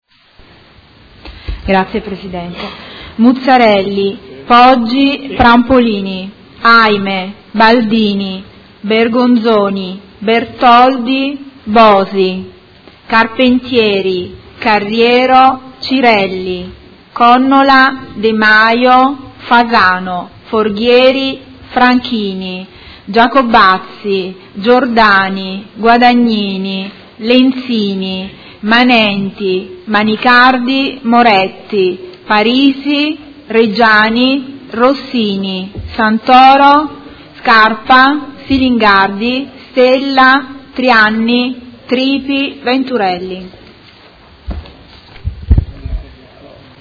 Seduta del 20/06/2019. Appello